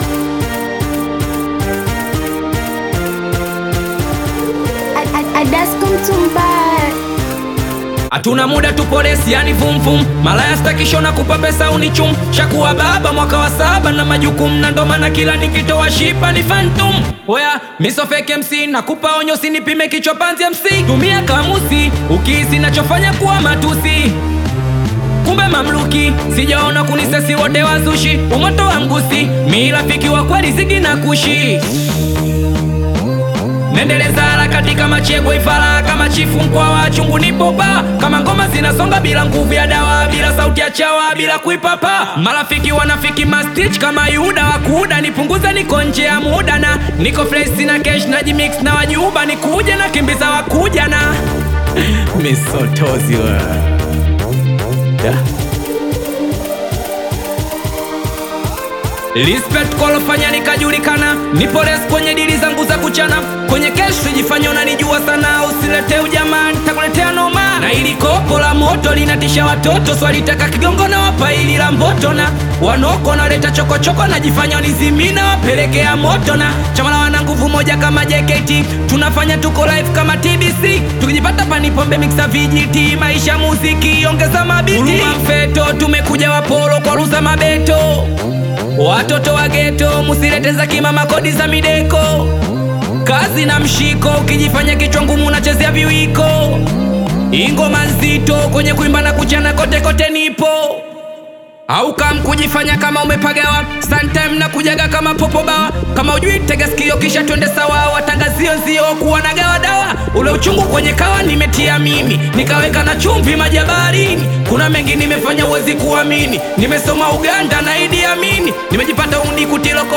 energetic Tanzanian Singeli/Bongo Flava single
Singeli